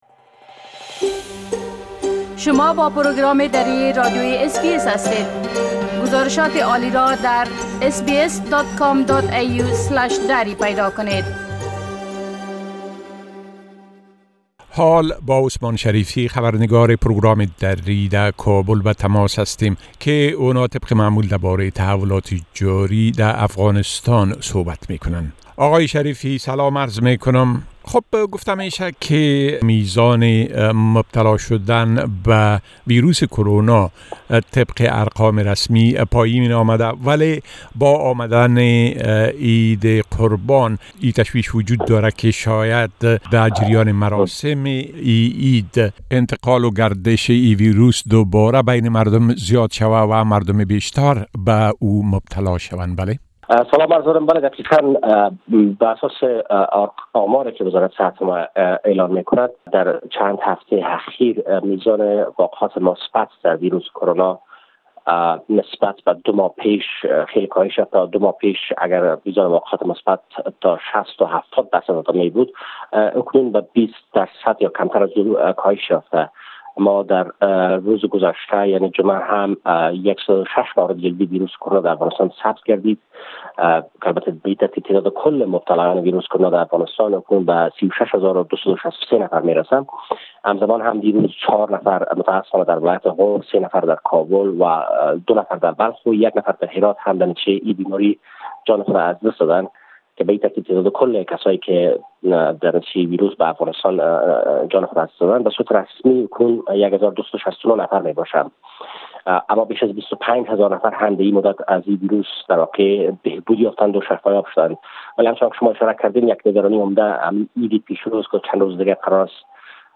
خبر نگار ما از کابل: مقامات نگران اند كە عدم مراعات توصيە ھاى صحى در جريان مراسم عيد بار ديگر حوادث ابتلا بە ويروس كرونا را افزايش خواھد داد.